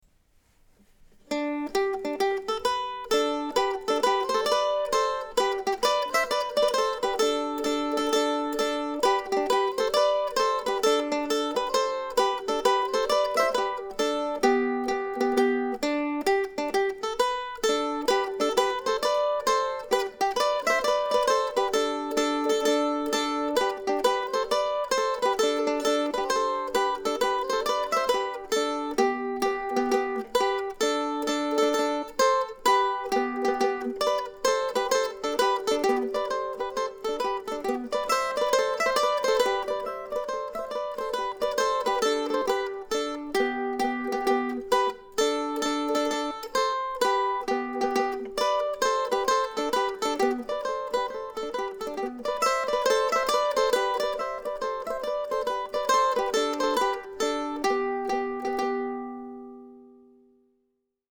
Today also celebrates the completion of my project to record all 18 of the duettinos published by William Bates around 1770.